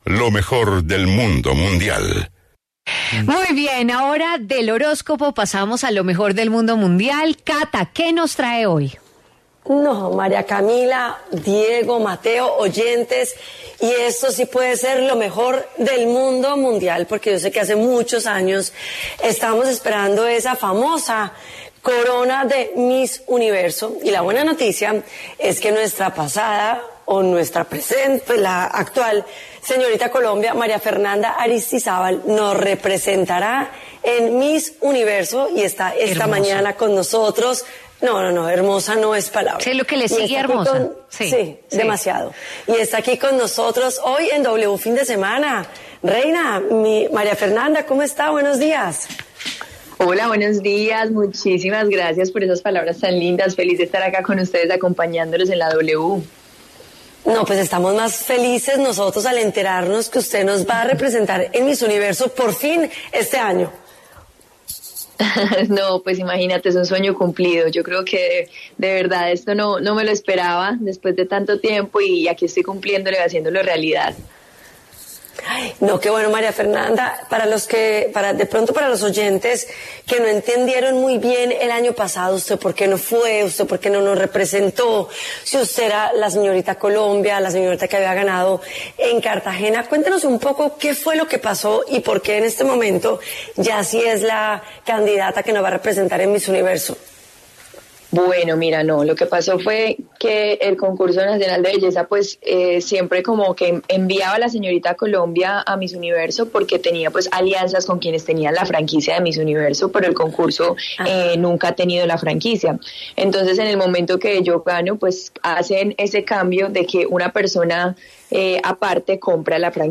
La señorita Colombia María Fernanda Aristizábal habló en W Fin de Semana sobre su participación en Miss Universe 2022.